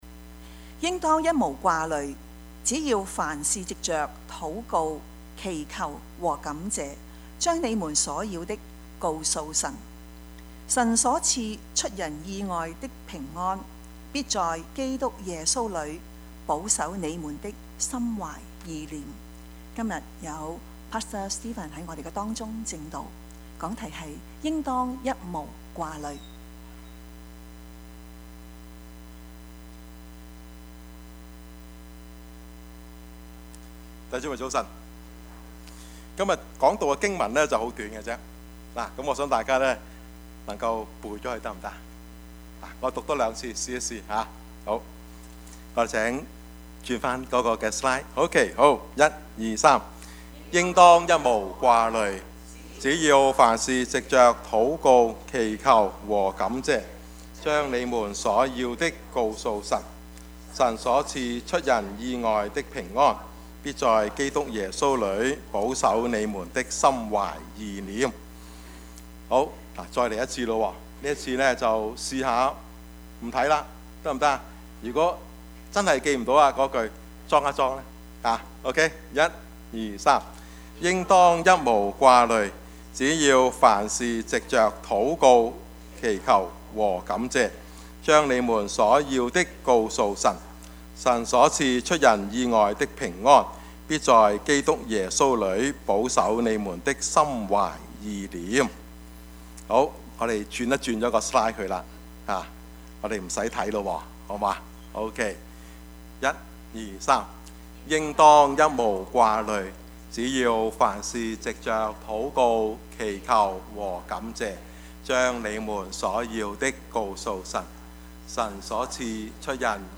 Service Type: 主日崇拜
Topics: 主日證道 « 禁不了的喜樂 施與受的喜樂 »